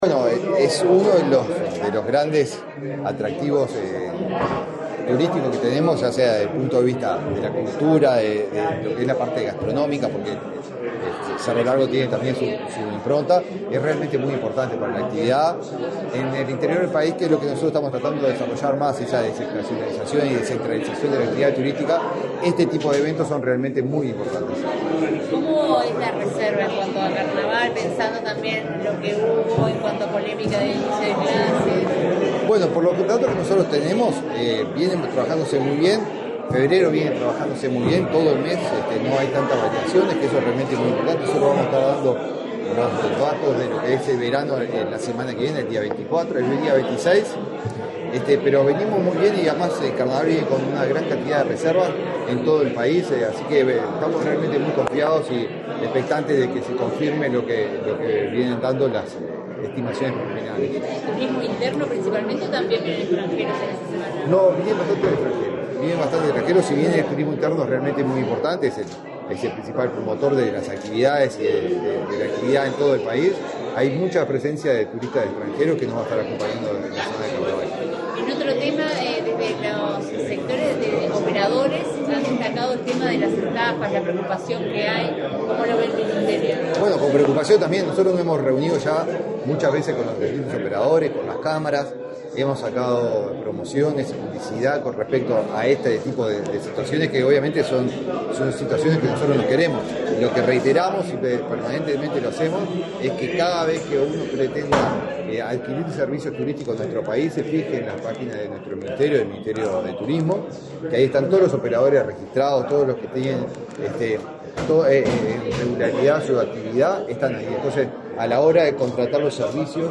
Declaraciones del ministro de Turismo, Eduardo Sanguinetti
El ministro de Turismo, Eduardo Sanguinetti, dialogó con la prensa en Montevideo, luego de encabezar el acto de lanzamiento del Carnaval de Melo 2025.